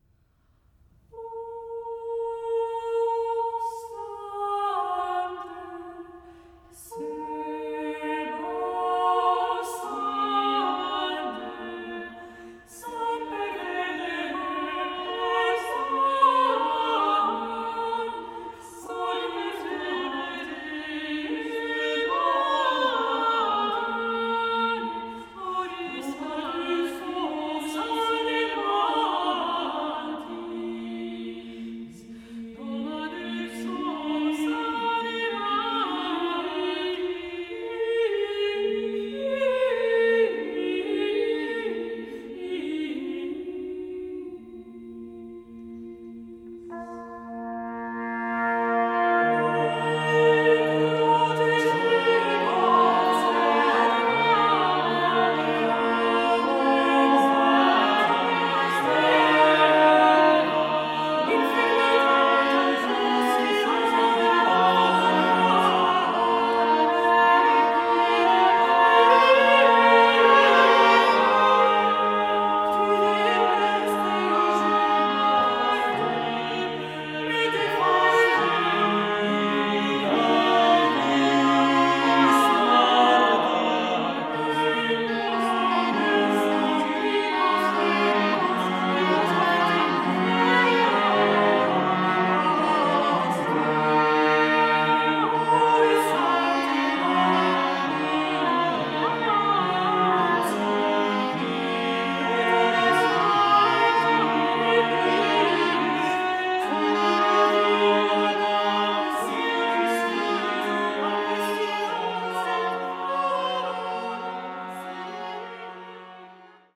met tot twintig zangers en instrumentalisten